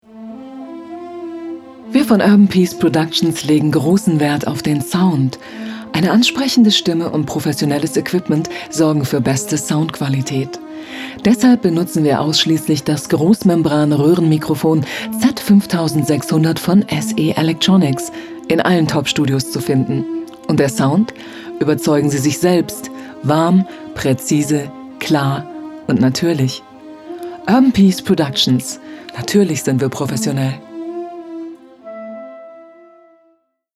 deutsche Sprecherin für Dokumentation, Synchron, Präsentationen, Podcasts/Internet, Moderation, Hörbücher, Stimmalter: junger Erwachsener - beste Jahre, Stimmklang von natürlich, sympathisch, kindlich, naiv über seriös, kompetent, geheimnisvoll, zart, erotisch, kühl und sachlich, bis hin zu nervend, zickig und launisch
Sprechprobe: Industrie (Muttersprache):
Female Voice Over for Documentation, Presentations, Podcasts / Internet, Moderation and Audiobooks, who also works as a voice actress.